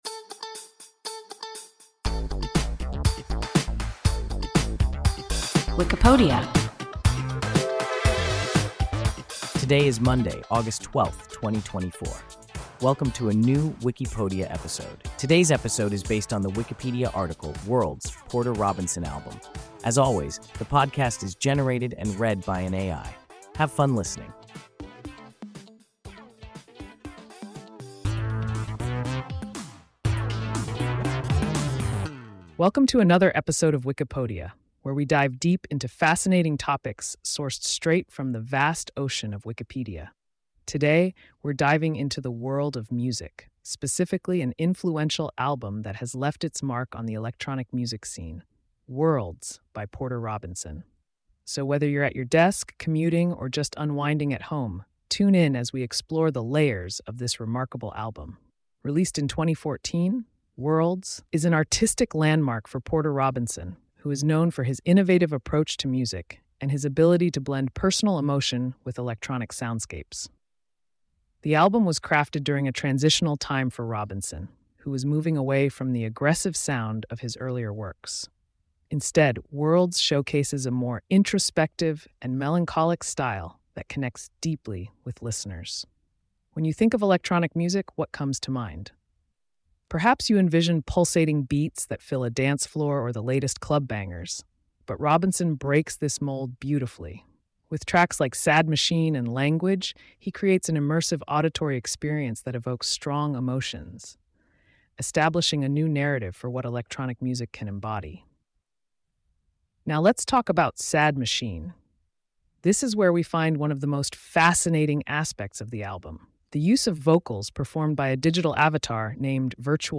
Wikipodia – an AI podcast